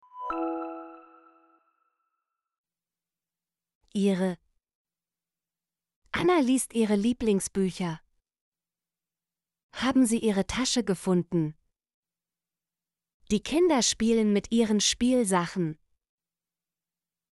ihre - Example Sentences & Pronunciation, German Frequency List